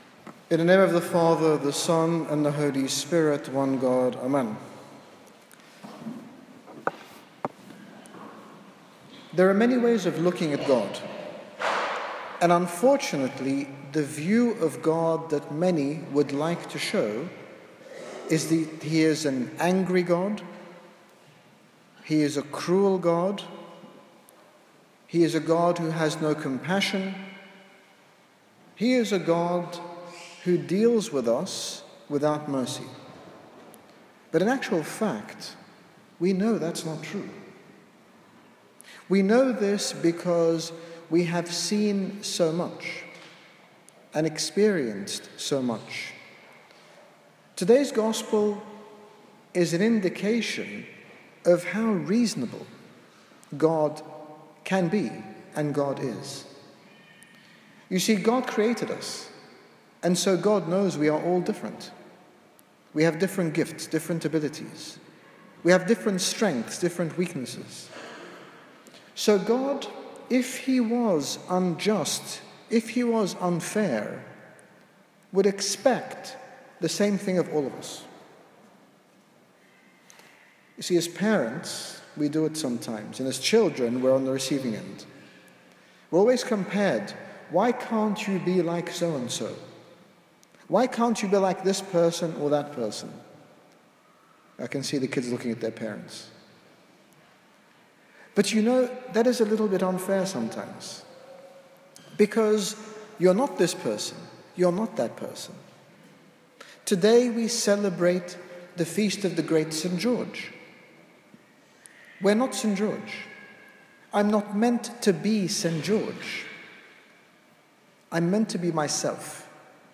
In this short sermon, His Grace Bishop Angaelos, General Bishop of the Coptic Orthodox Church in the United Kingdom, speaks to us about what God expects of us, and that we need to be the best we can; not trying to be anyone but ourselves.